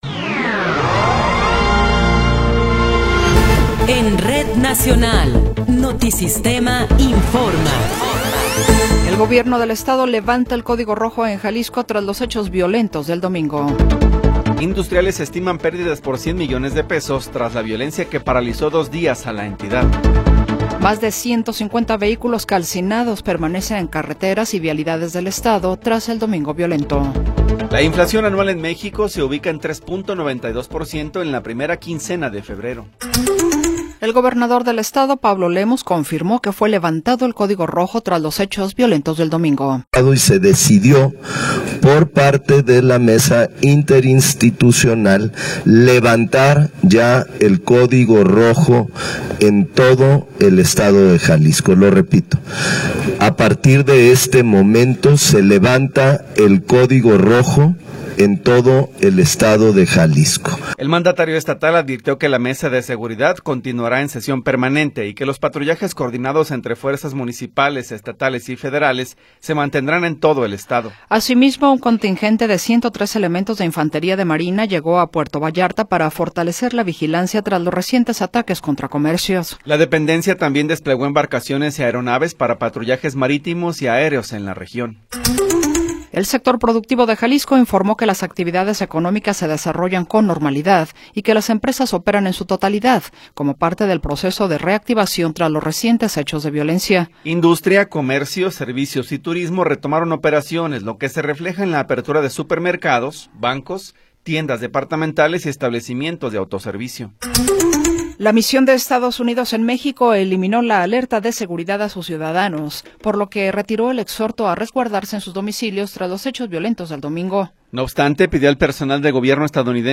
Noticiero 14 hrs. – 24 de Febrero de 2026
Resumen informativo Notisistema, la mejor y más completa información cada hora en la hora.